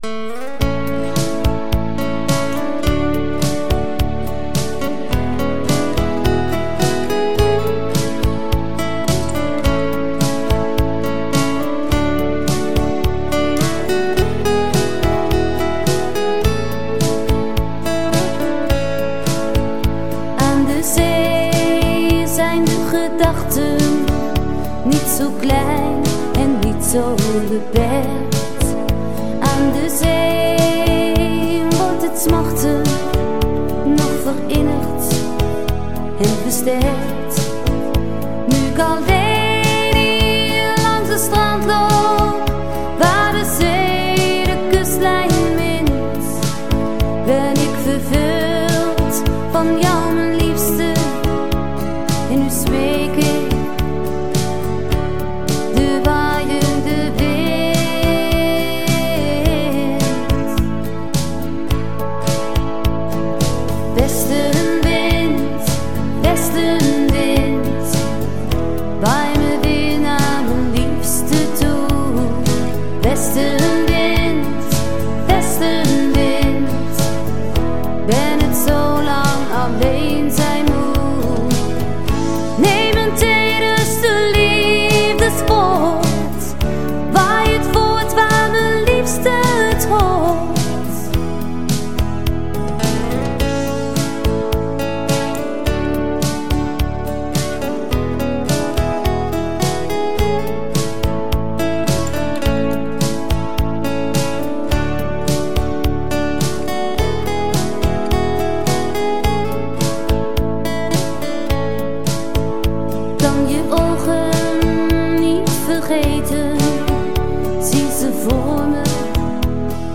allround band
Zang
Slagwerk, zang en presentatie
Gitaar en zang
Basgitaar
Toetsen en zang